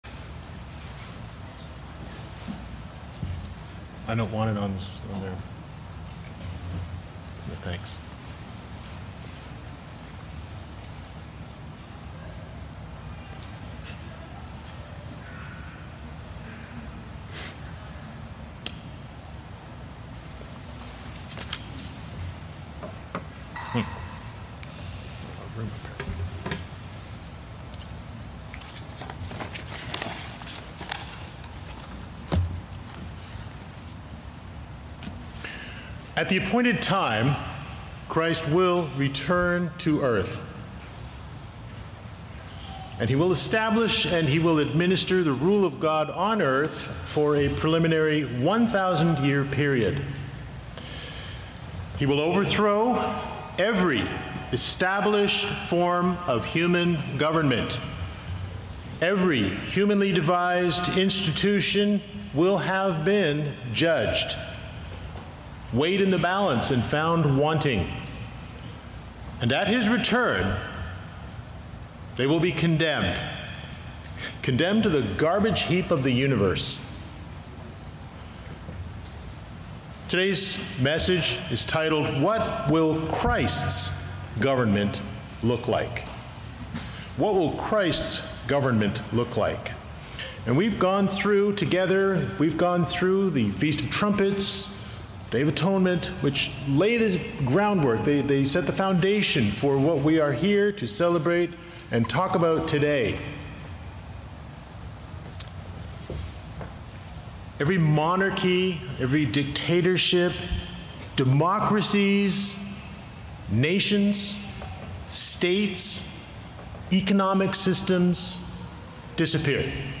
This sermon was given at the Lancaster, Pennsylvania 2021 Feast site.